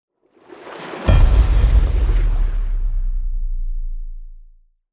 دانلود صدای آب 19 از ساعد نیوز با لینک مستقیم و کیفیت بالا
جلوه های صوتی